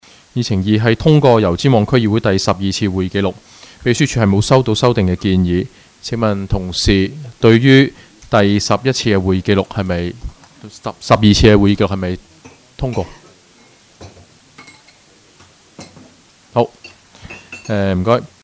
区议会大会的录音记录
油尖旺区议会会议室